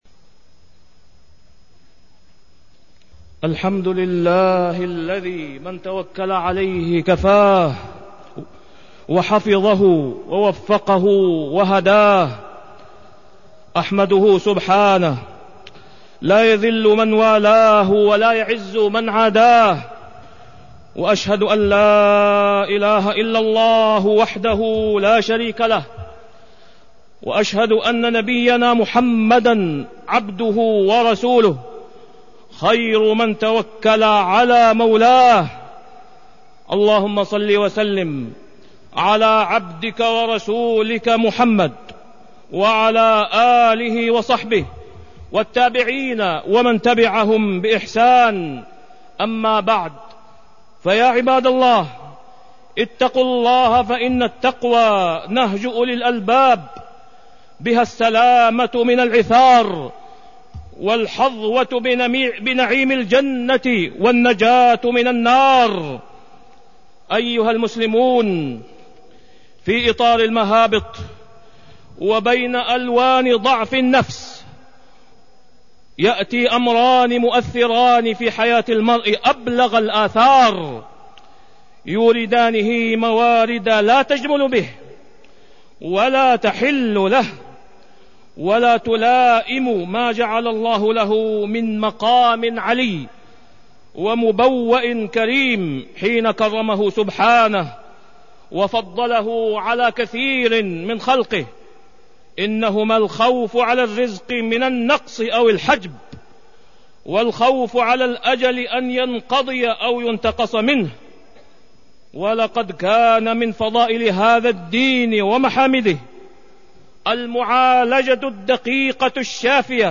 تاريخ النشر ٢٧ شوال ١٤٢٢ هـ المكان: المسجد الحرام الشيخ: فضيلة الشيخ د. أسامة بن عبدالله خياط فضيلة الشيخ د. أسامة بن عبدالله خياط مراتب اليقين